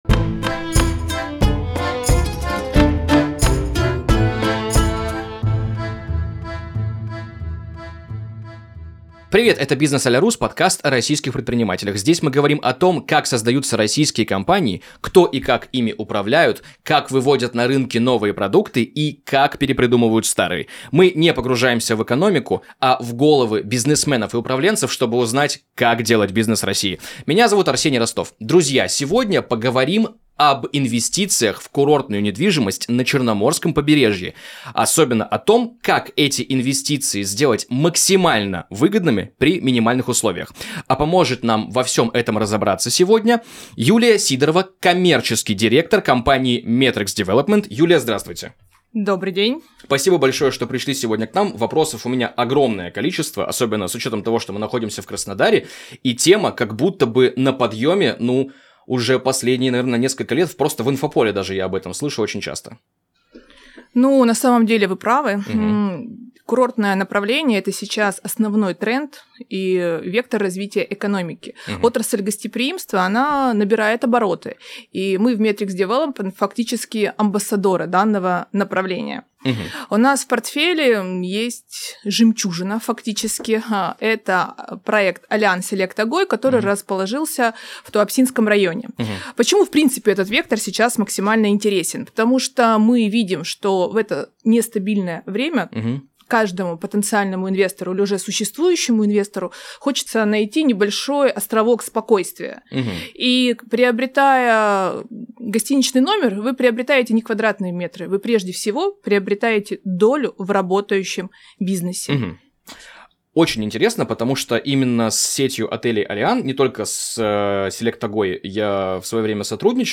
Обсуждаем, каково это — делать бизнес по-русски. Берём интервью у предпринимателей нашей страны, чьи компании меняют локальный и федеральный рынок.